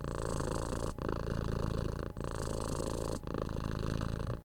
cat_purr_long.ogg